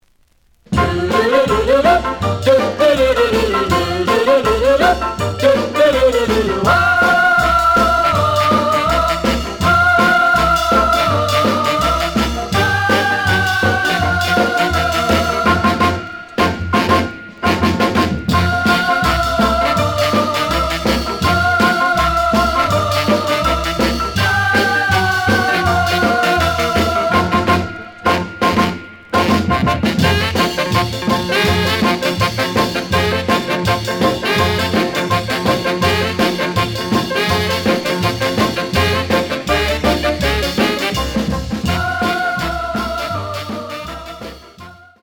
The audio sample is recorded from the actual item.
●Genre: Rhythm And Blues / Rock 'n' Roll
Slight edge warp. But doesn't affect playing. Plays good.